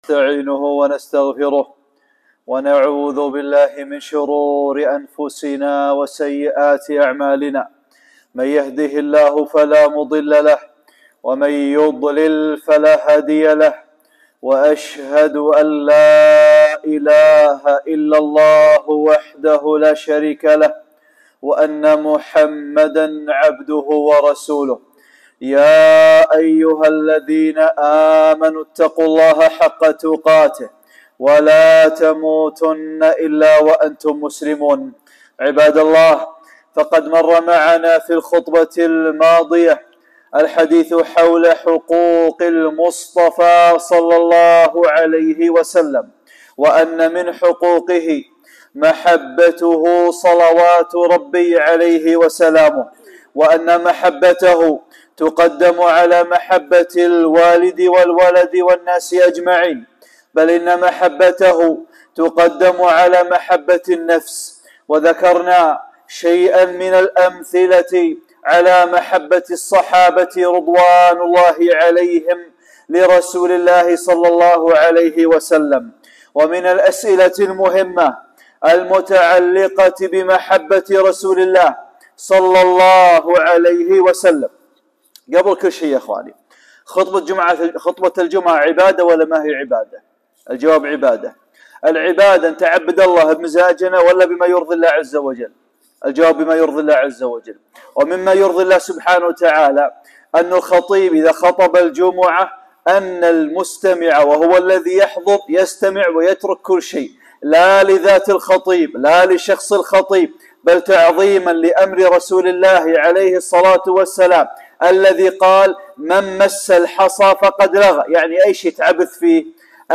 خطبة - (3) تعظيم رسول الله ﷺ | حقوق المصطفى ﷺ